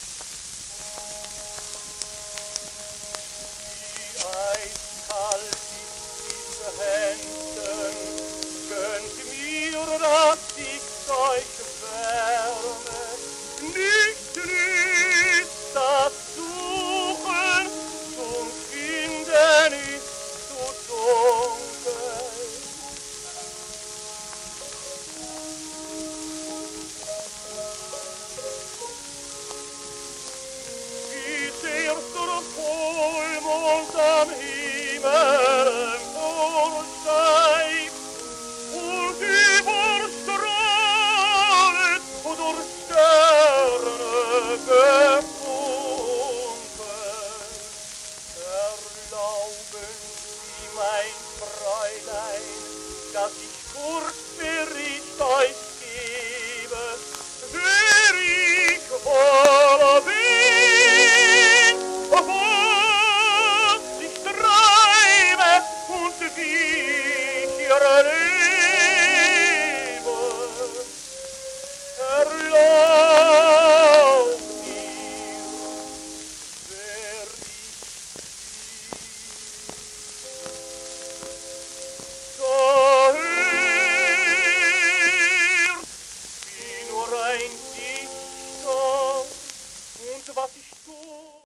レオ・スレザーク(Ten:1873-1946)
w/オーケストラ
盤質B キズ音有,面スレ,盤反り
ドイツ語歌唱
ドイツのテノールで大変な人気を誇った、レオ・スレザーク。